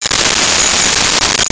Hand Scanner
Hand Scanner.wav